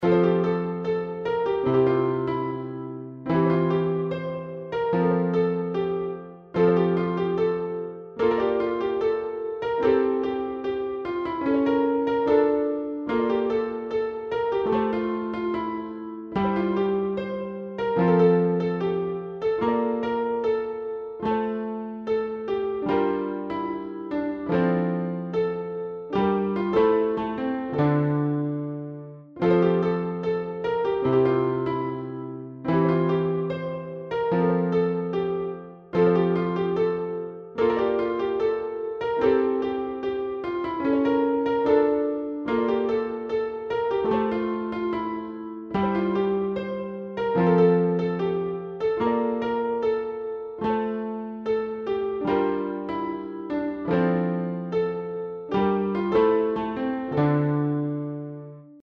BGM
ショート明るい穏やか